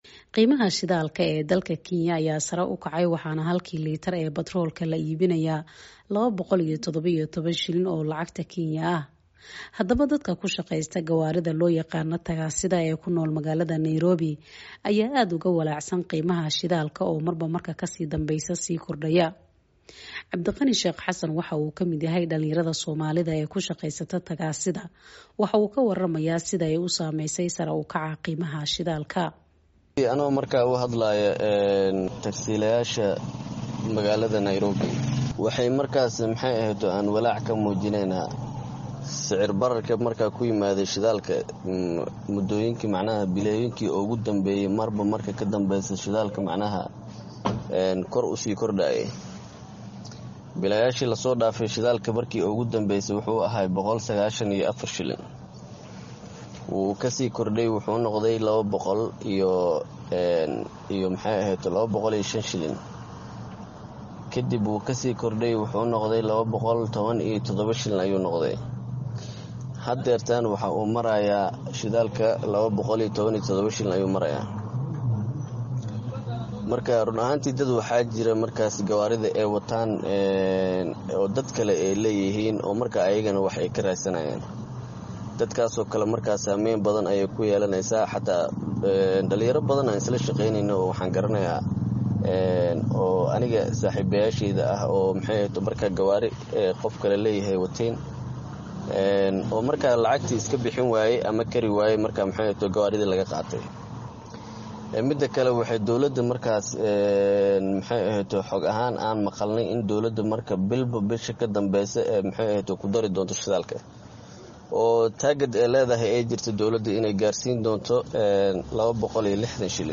Warbixintan waxaa soo dirtay weriyaha VOA